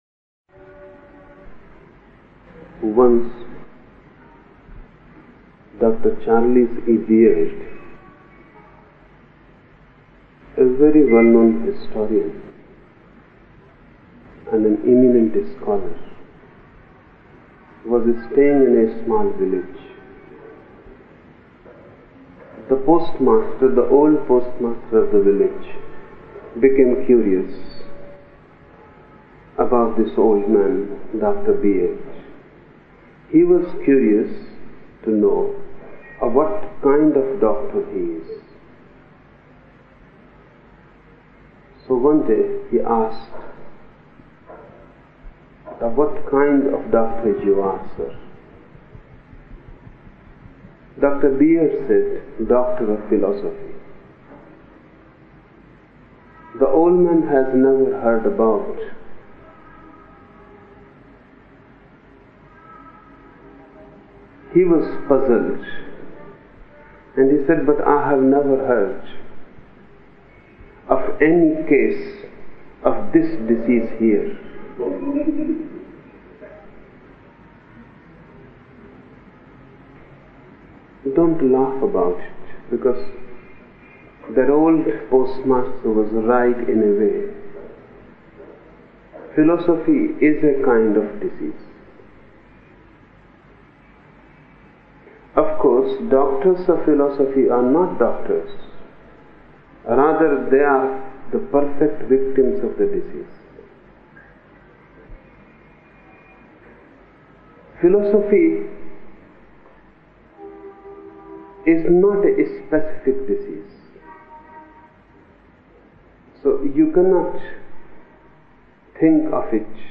Each program has two parts, Listening Meditation (Osho discourse) and Satsang Meditation.